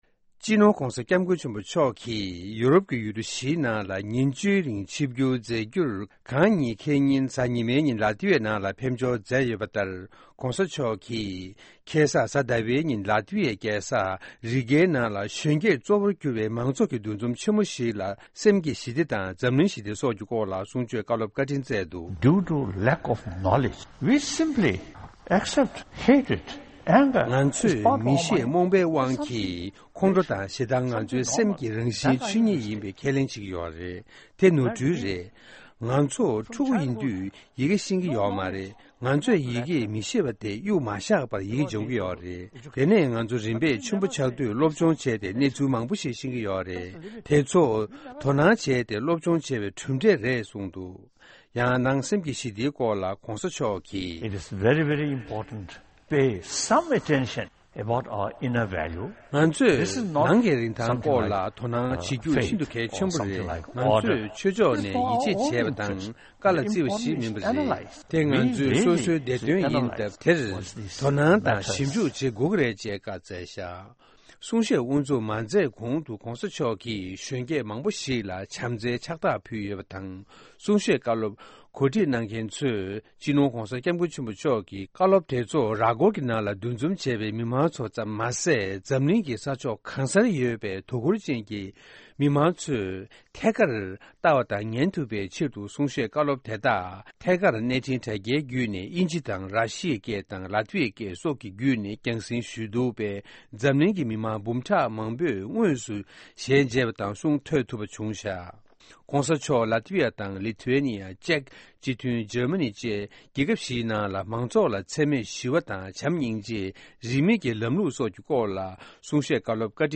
༧གོང་ས་མཆོག་གིས་རི་གྷཱའི་ནང་མང་ཚོཌ་ལ་གསུང་བཤད་གནང་ཡོད་པ་།